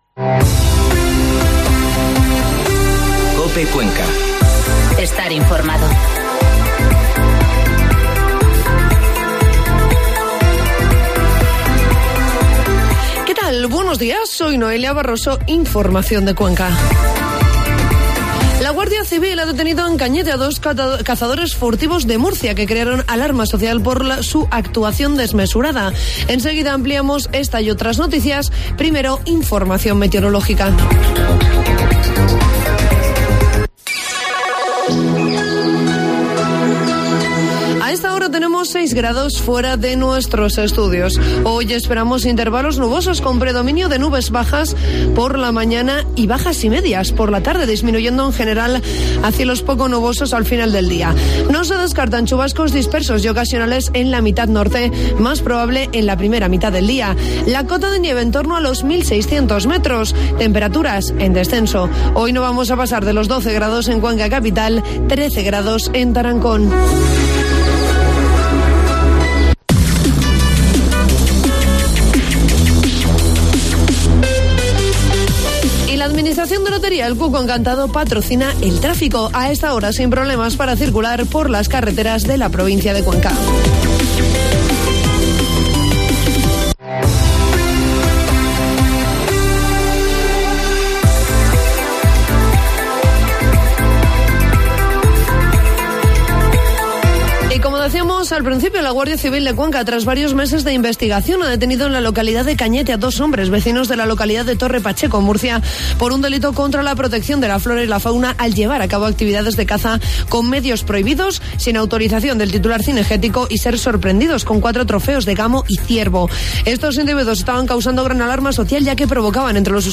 Informativo matinal COPE Cuenca 5 de noviembre